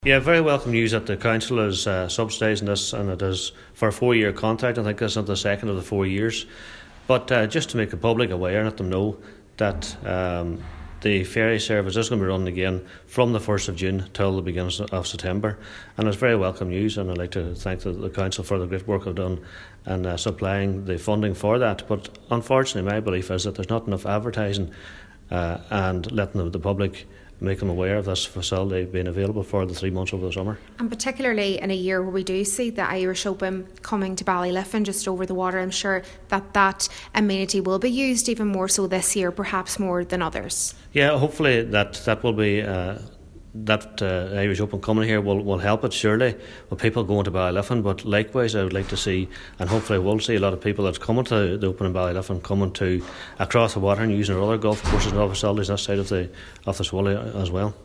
Cllr. Liam Blaney is hopeful that the ferry will be utilised by those attending the Ballyliffiin Irish Open in July: